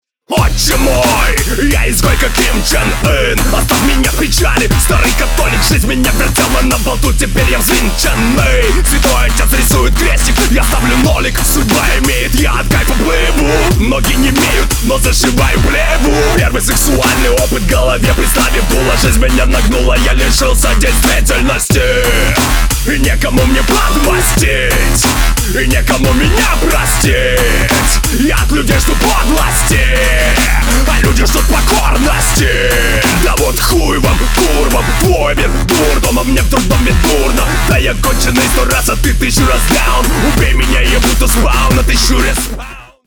• Качество: 320, Stereo
жесткие
Альтернатива
злые
инди
Рэп-рок
с матом